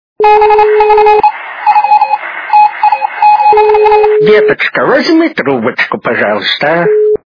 При прослушивании Азбука Морзе и голос старушки - Деточка, возьми трубочку качество понижено и присутствуют гудки.
Звук Азбука Морзе и голос старушки - Деточка, возьми трубочку